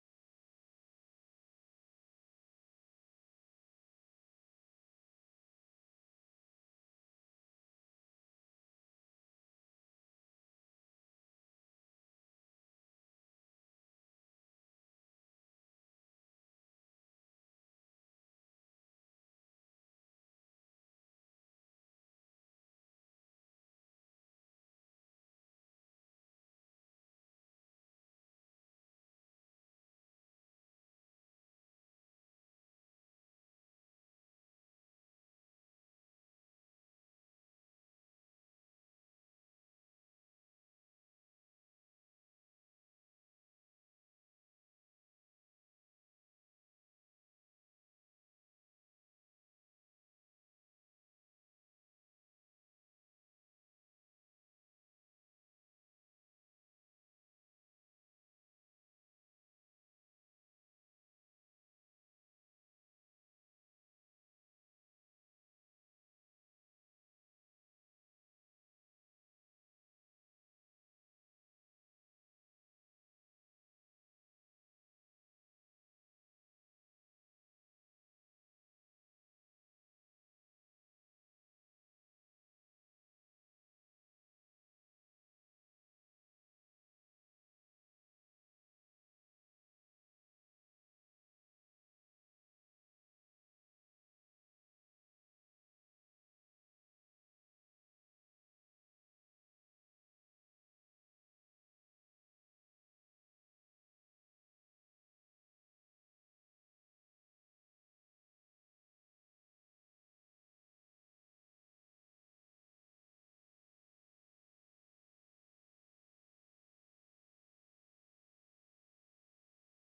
Město Varnsdorf: 15. zasedání Zastupitelstva města Varnsdorf Místo konání: Střelnice, Mariánská 476, Varnsdorf Doba konání: 28. listopadu 2024 od 15:00 hod. 1.